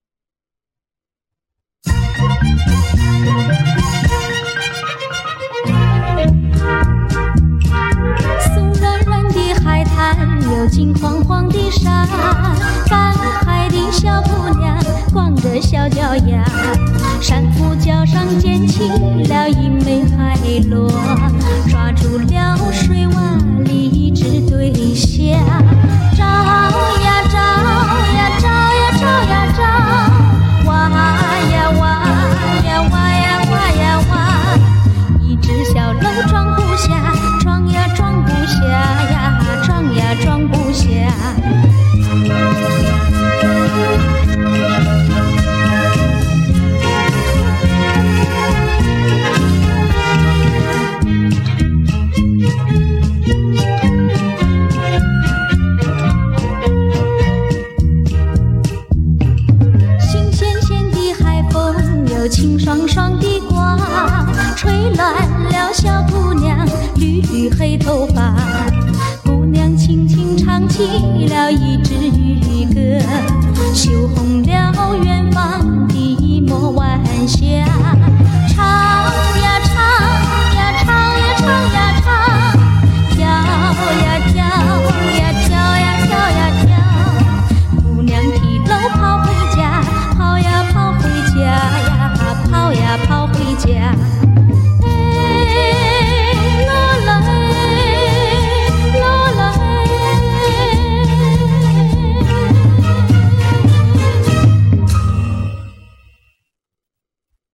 不过您也能听出她以情感人声情并茂的特点。